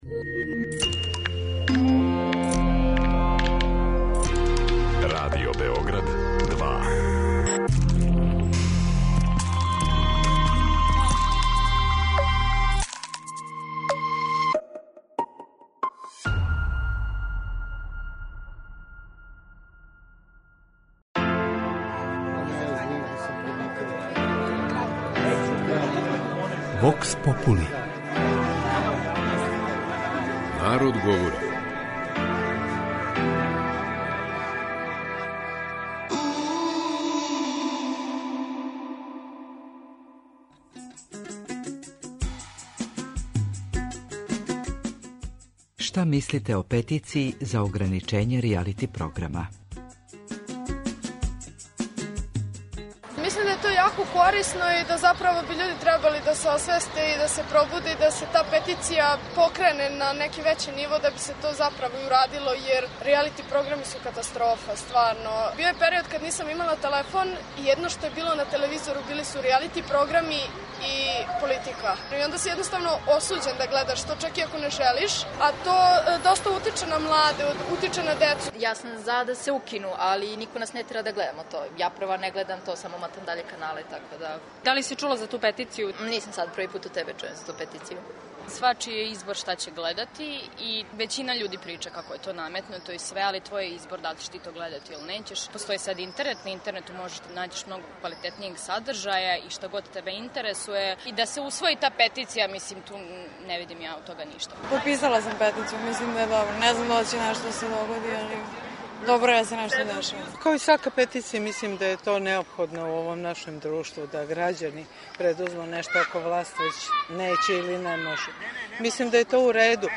Питали смо грађане шта мисле о овој петицији коју је подржало 40 хиљада људи.